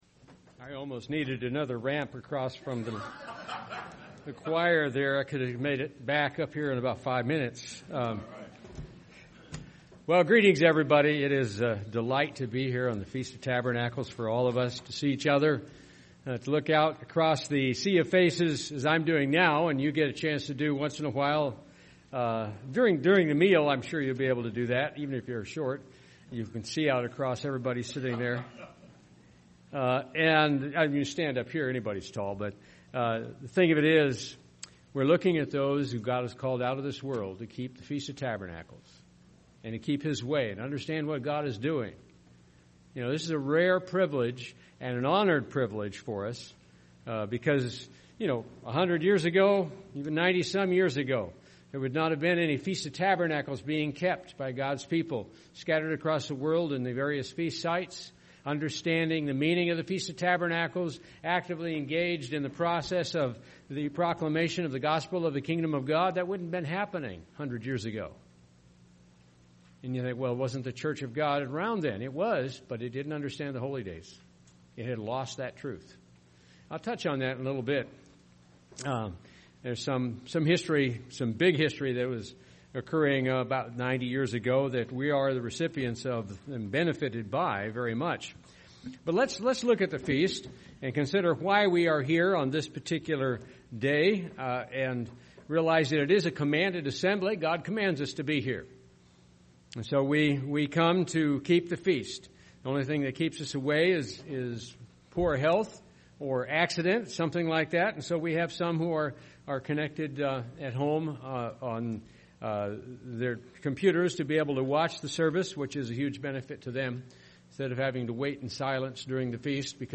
This sermon was given at the Cincinnati, Ohio 2018 Feast site.